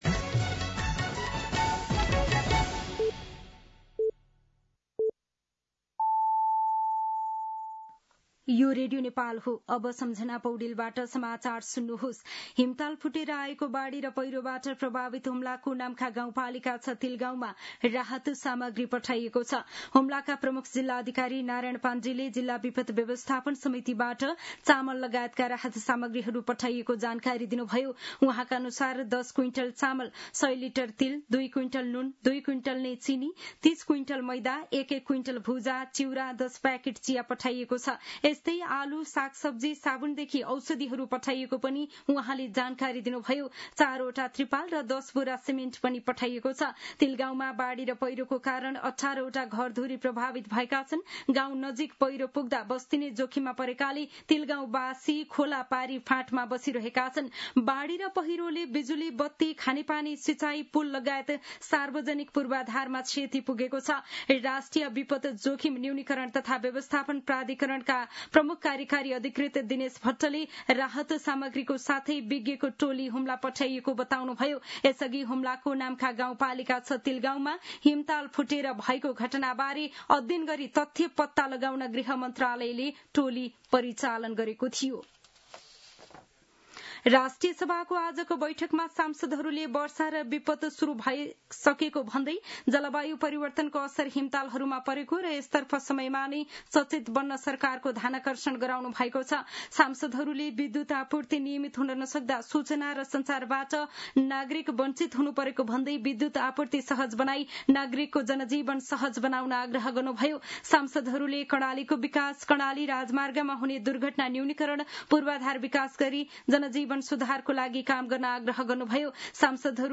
दिउँसो ४ बजेको नेपाली समाचार : ७ जेठ , २०८२
4-pm-Nepali-News-02-07.mp3